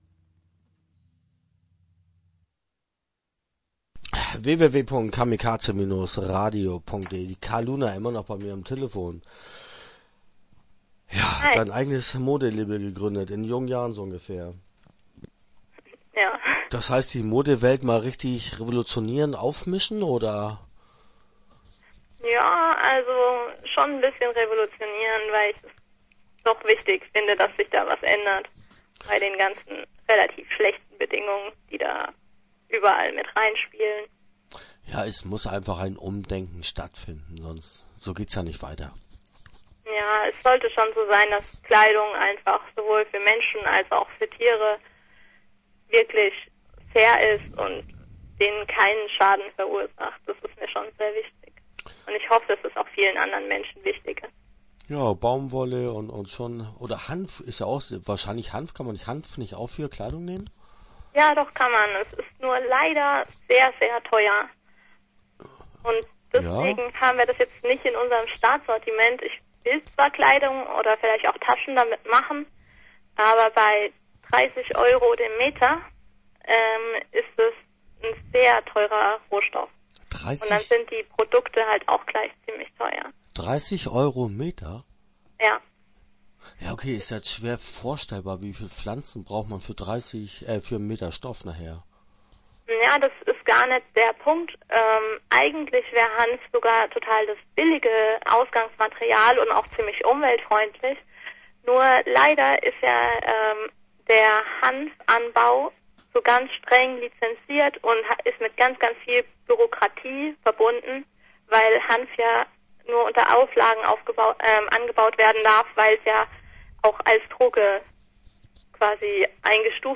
Rantipole - Interview Teil 1 (8:27)